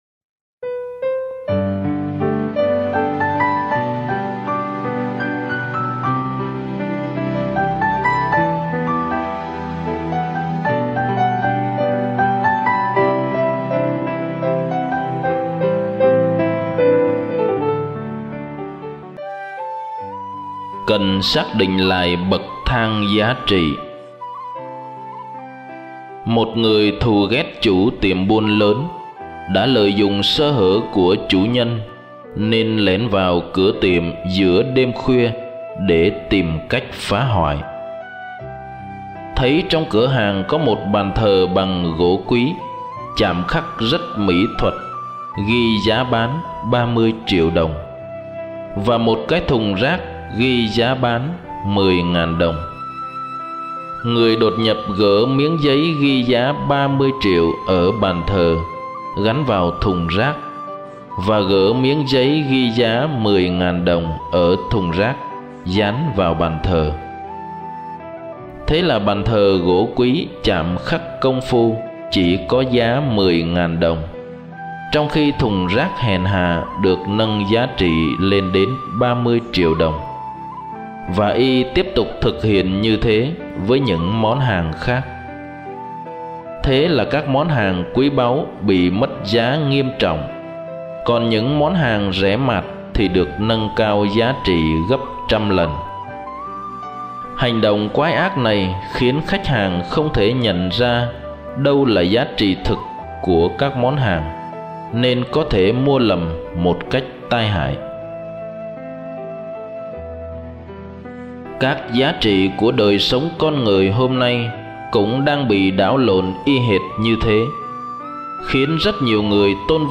(Suy niệm Tin Mừng Mác-cô (6, 1-6) trích đọc vào Chúa Nhật 14 thường niên)